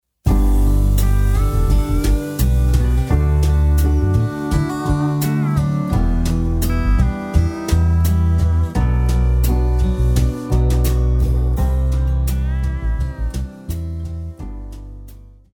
Classical
French Horn
Band
Etude,Course Material,Classical Music
Only backing